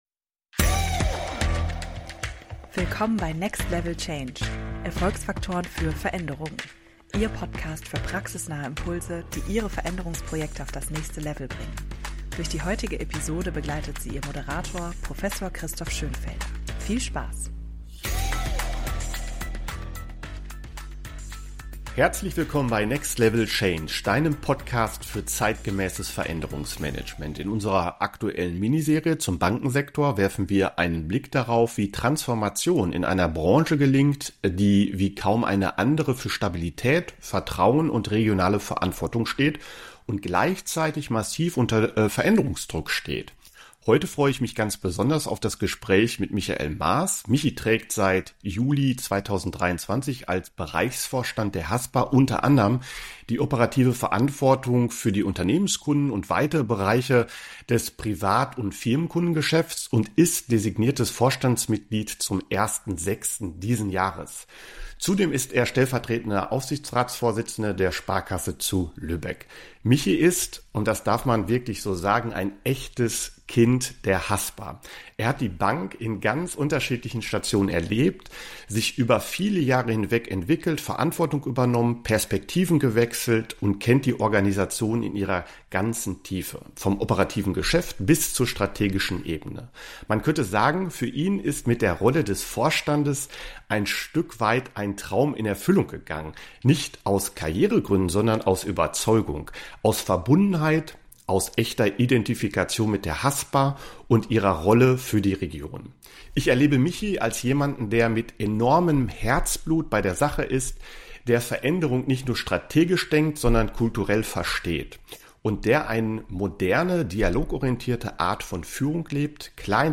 Ein Gespräch über Purpose, Performance-Dialoge auf der Schaukel statt im Konferenzraum – und darüber, wie kulturelle Veränderung wirklich verankert wird.